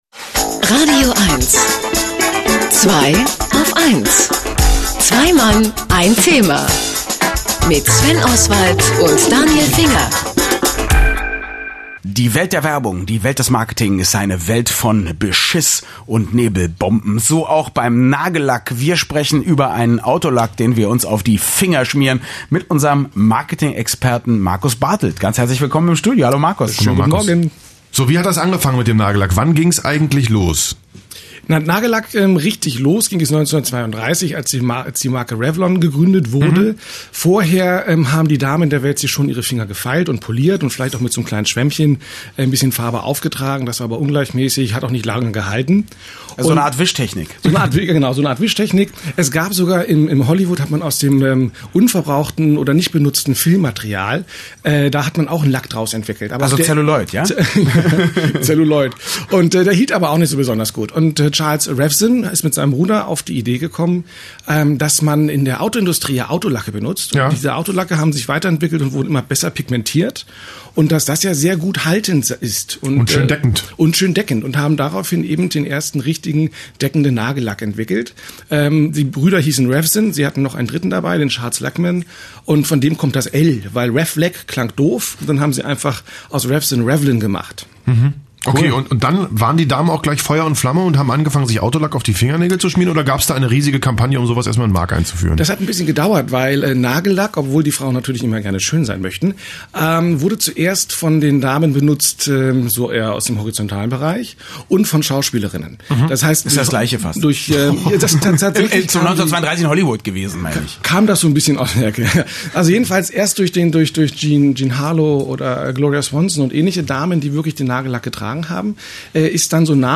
Und wieder begeben wir uns auf die Erinnerungsstraße und entdecken längst vergessene Perlen meiner radioeins-Interviews wieder….